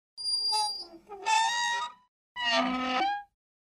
Звуки металла
Скрип при вращении металла (версия 2)